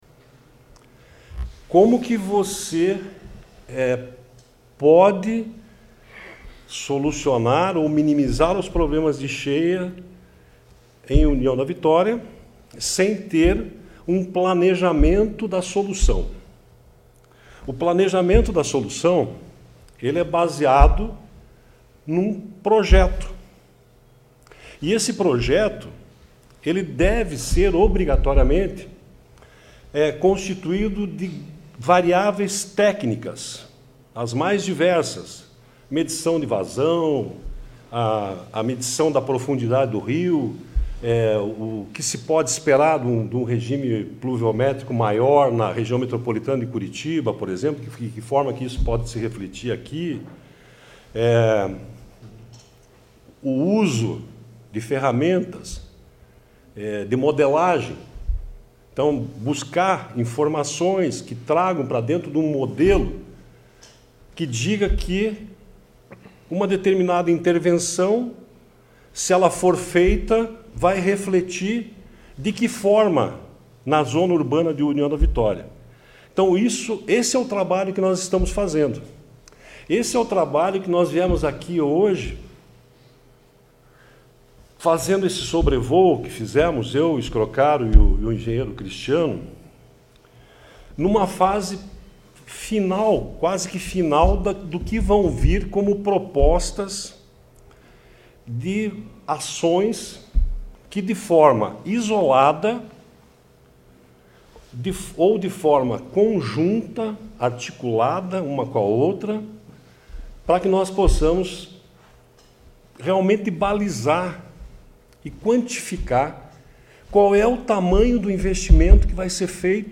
Durante a coletiva, foi destacado que a geografia de União da Vitória — localizada em uma área de confluência e cercada por morros — a torna naturalmente mais suscetível a alagamentos severos.
O diretor-presidente do IAT, Everton Souza, iniciou a coletiva dando ênfase ao projeto que está em andamento, e que conta  com a melhor equipe e as melhores ferramentas para fazer o estudo técnico do rio Iguaçu e tentar mitigar o impacto da enchentes na região